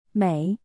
(měi) — beautiful